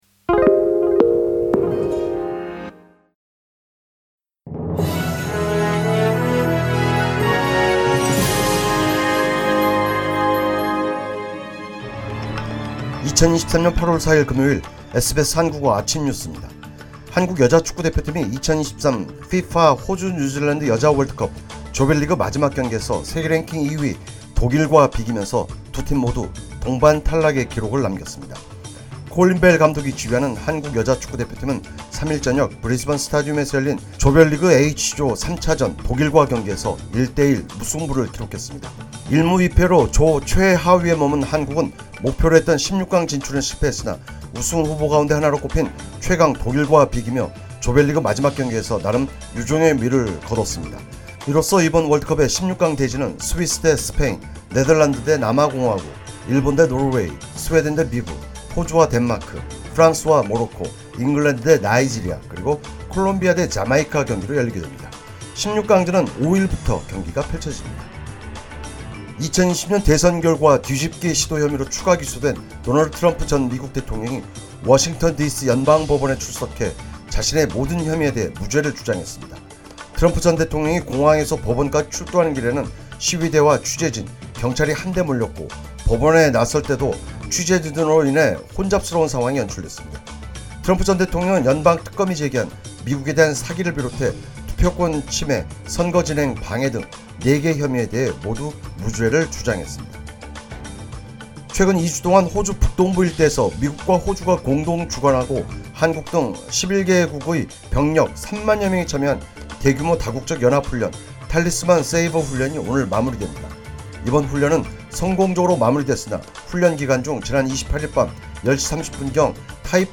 SBS 한국어 아침뉴스: 2023년 8월4일 금요일
2023년 8월4일 금요일 SBS 한국어 아침뉴스입니다.